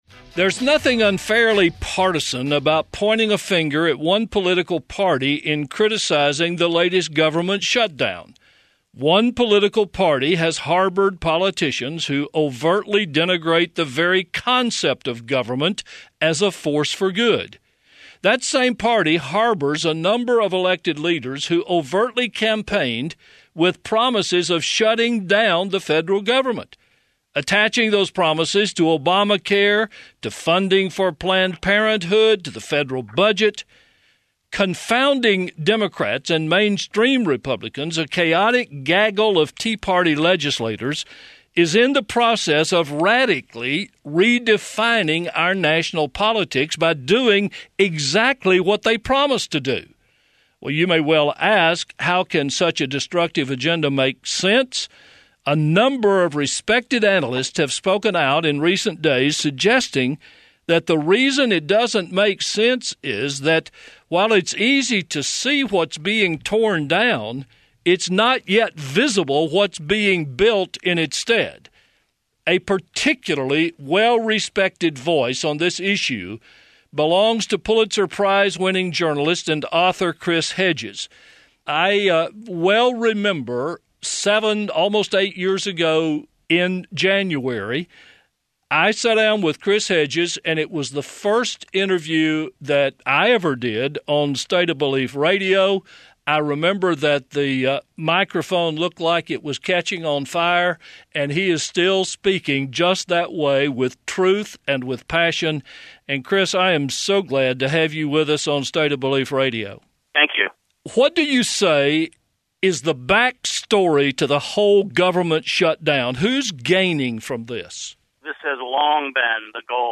CLICK HERE FOR EXTENDED INTERVIEW VIDEO AND TRANSCRIPT.